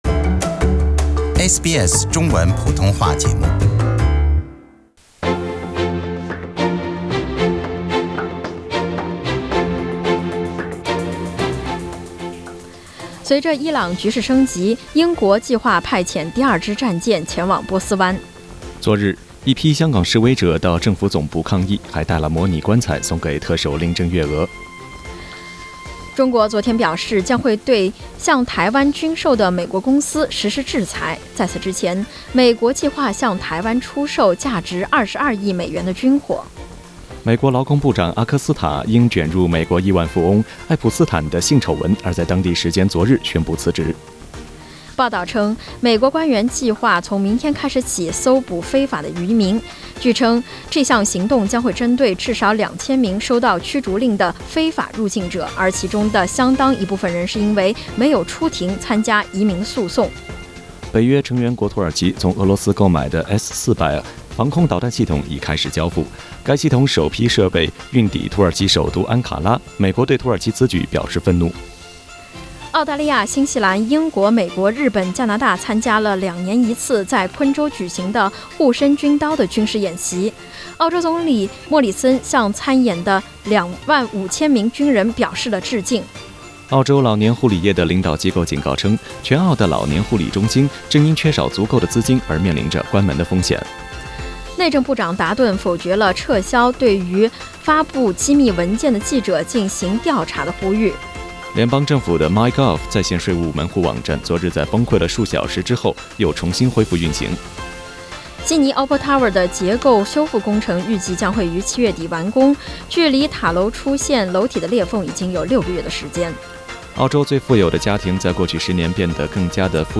SBS 早新闻 （07月13日）